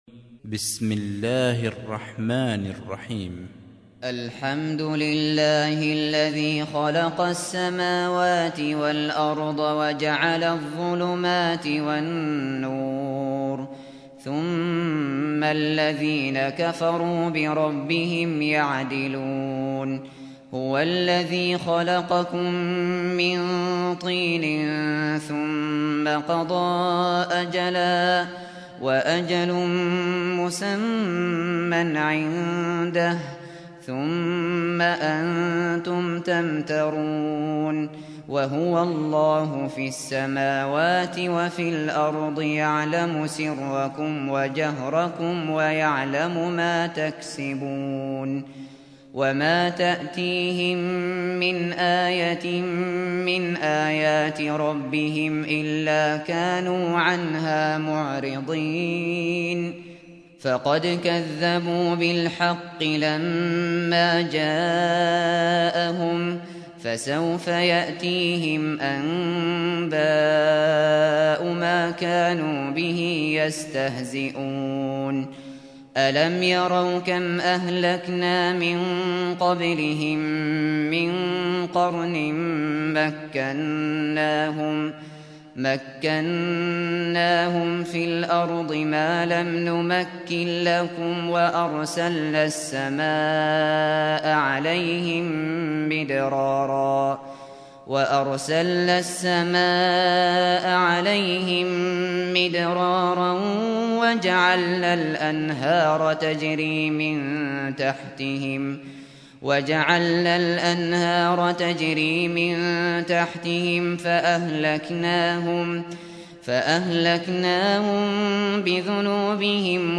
سُورَةُ الأَنۡعَامِ بصوت الشيخ ابو بكر الشاطري